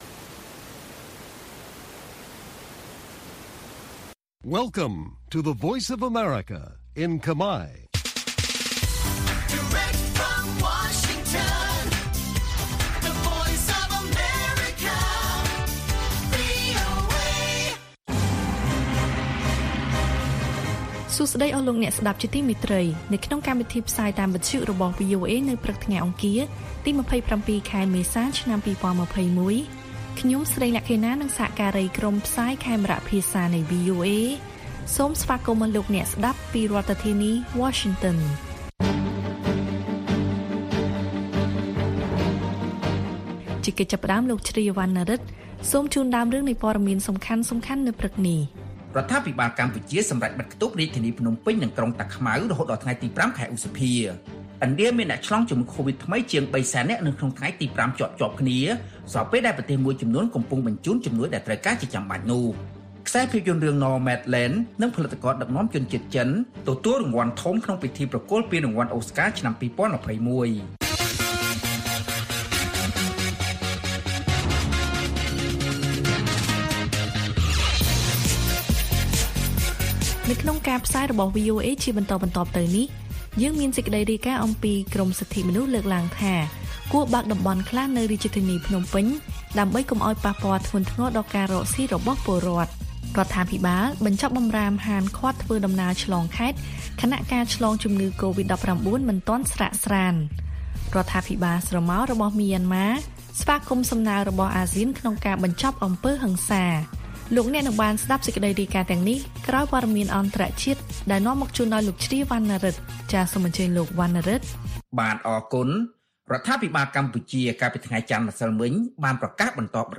ព័ត៌មានពេលព្រឹក៖ ២៧ មេសា ២០២១